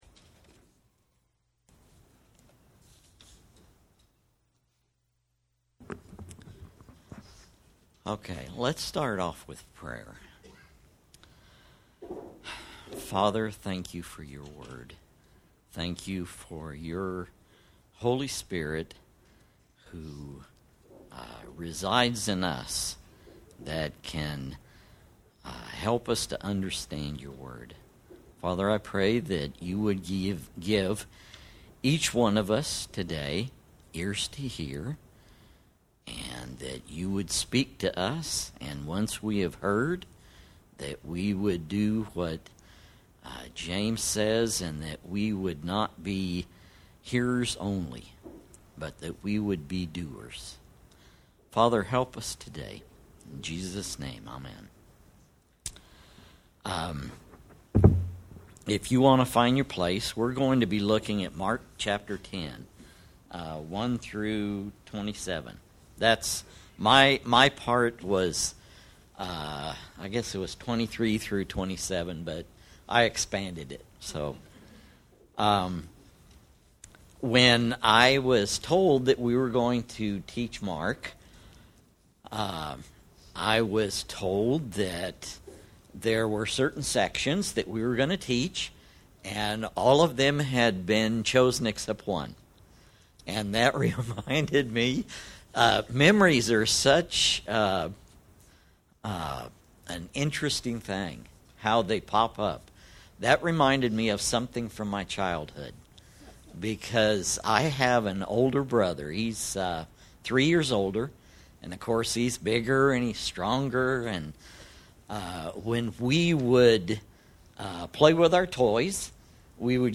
Passage: Mark 10 Service Type: Sunday Morning